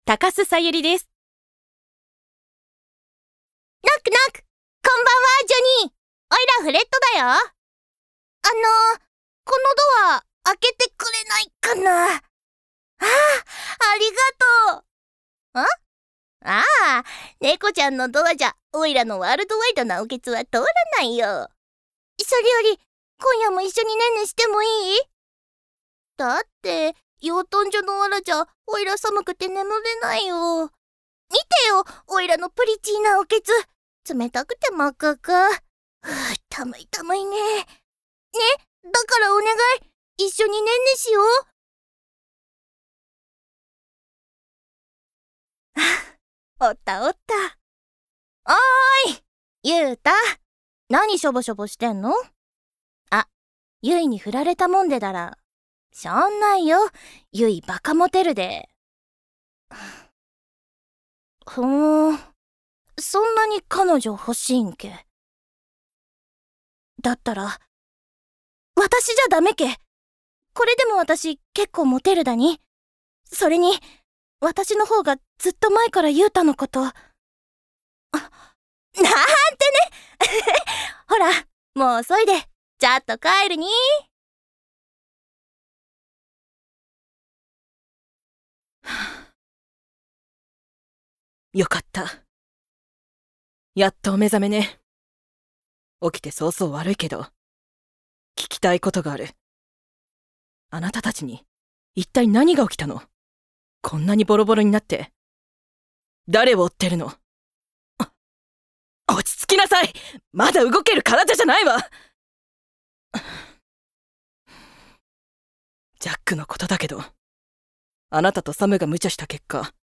誕生日： 7月4日 血液型： AB型 身 長： 157cm 出身地： 静岡県 趣味・特技： 食べること 資格： 珠算検定弐段・暗算一段 音域： -F3〜B4 方言： 遠州三河弁
VOICE SAMPLE